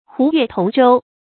胡越同舟 注音： ㄏㄨˊ ㄩㄝˋ ㄊㄨㄙˊ ㄓㄡ 讀音讀法： 意思解釋： 猶言吳越同舟。